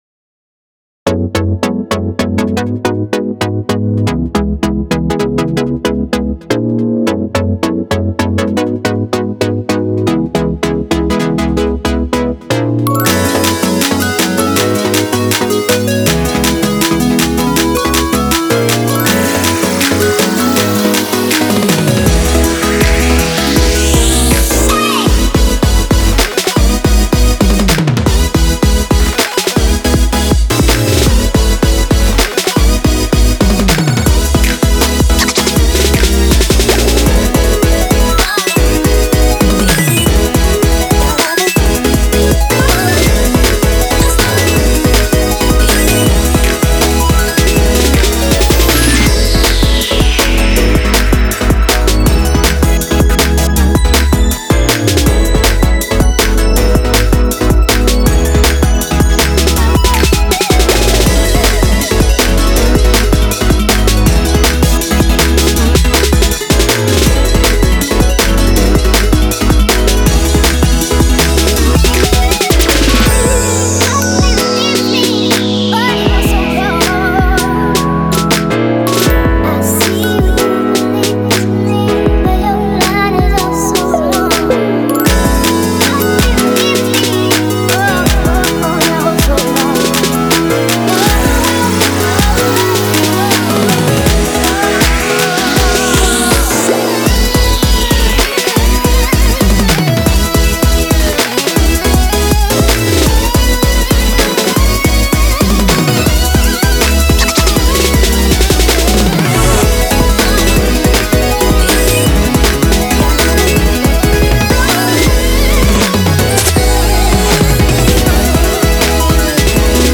BPM80-160
Audio QualityPerfect (High Quality)
Genre: FUTURE BASS.